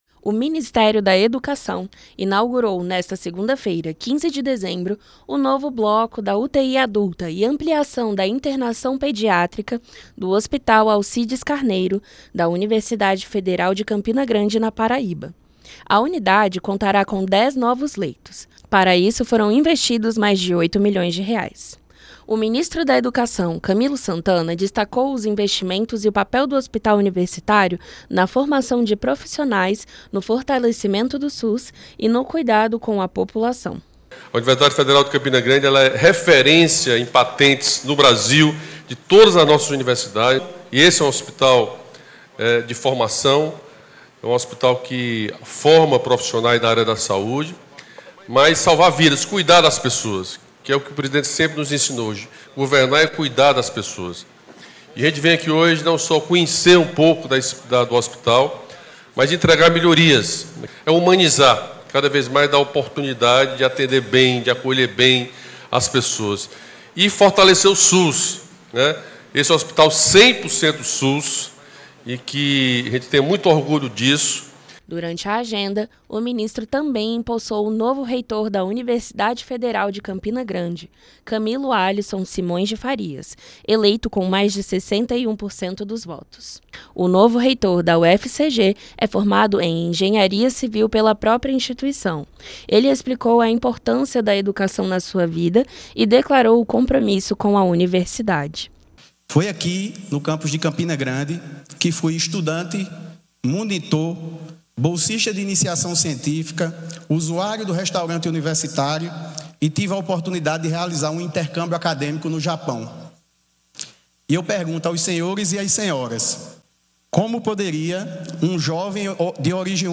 22/03/22 - Pronunciamento do Ministro do Desenvolvimento Regional, Rogério Marinho